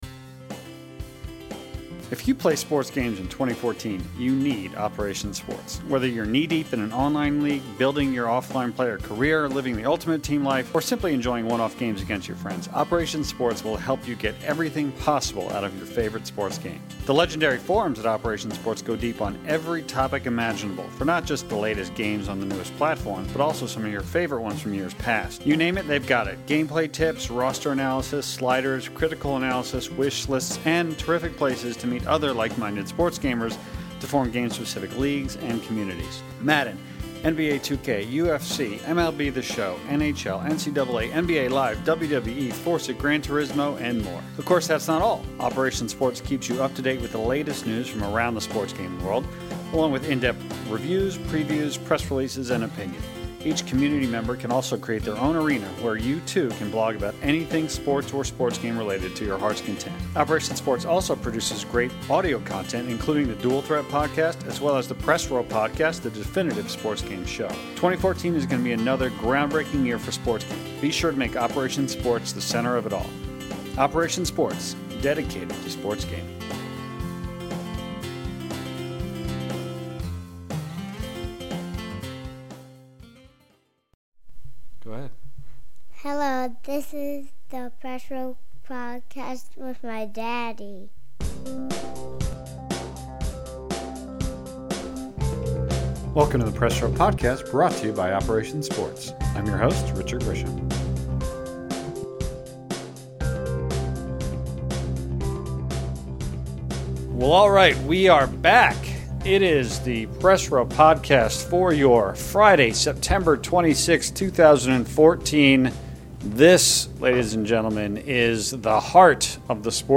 Ep 102 - FIFA 15 Review Roundtable